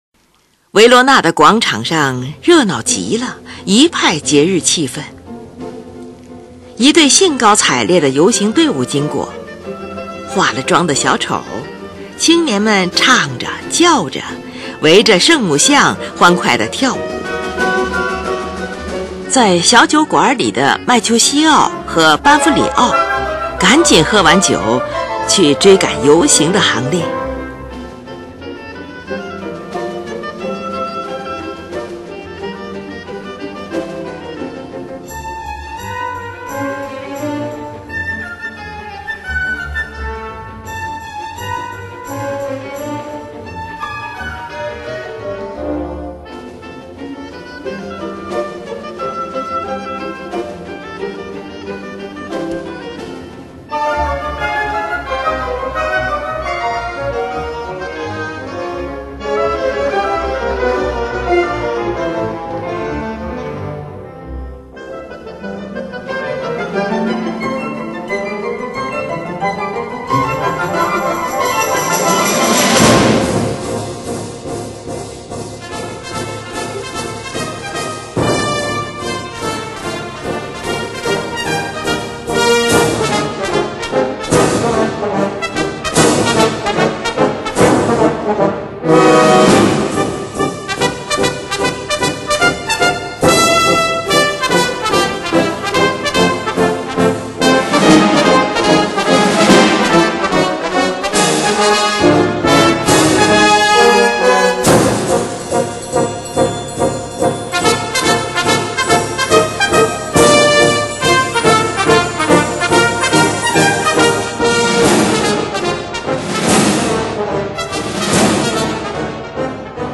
三幕芭蕾舞剧《罗密欧与朱丽叶》作于1935年至1936年间，剧本根据莎士比亚同名戏剧改编。
它的音乐继承并发展了柴可夫斯基舞剧音乐的交响性原则，以特有的深刻抒情性和戏剧性著称于世。美妙动人、丰富多彩的旋律与和声，复杂多样化的节奏以及雄魄壮阔、精致华丽的配器，把舞剧音乐提高到一个崭新的时代高度。